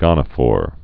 (gŏnə-fôr)